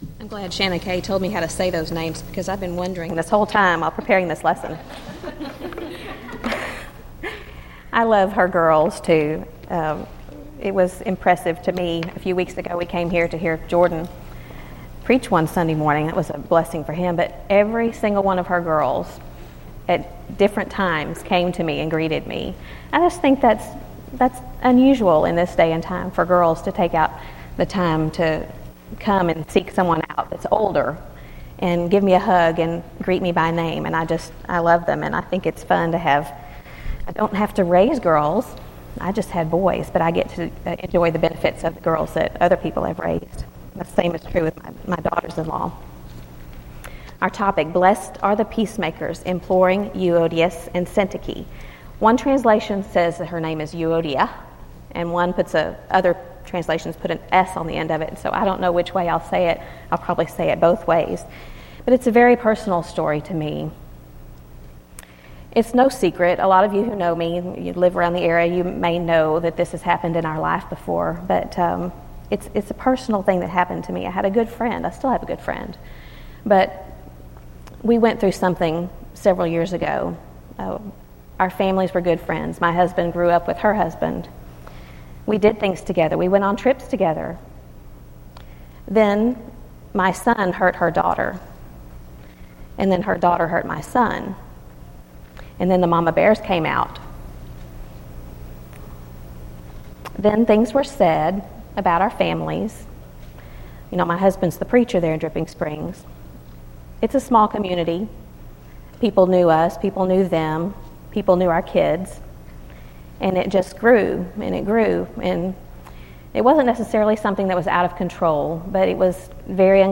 Event: 2014 Focal Point
lecture